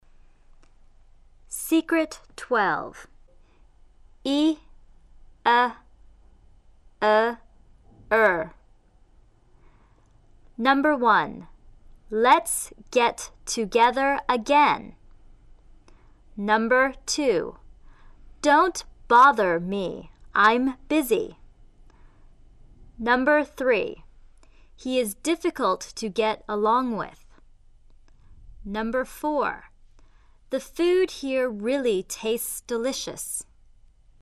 短元音急促有力
——调动腹部的力量，一收小腹，立刻纯正